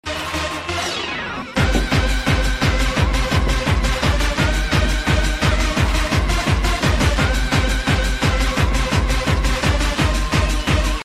moving to upbeat techno like they just won the battle of their life